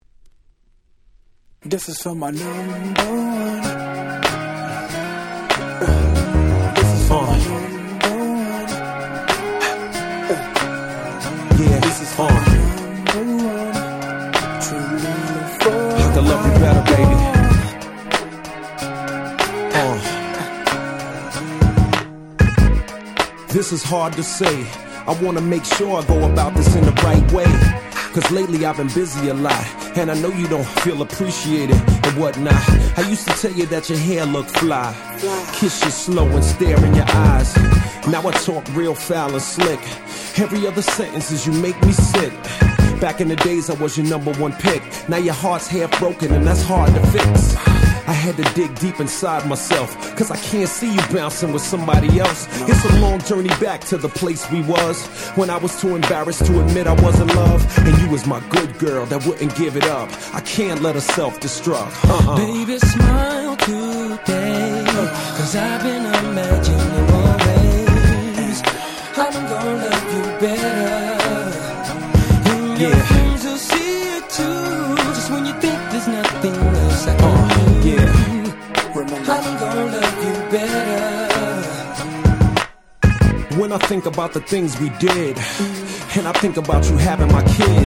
05' Smash Hit Hip Hop !!
キャッチー系